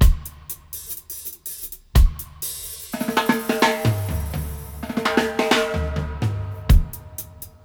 121-FX-02.wav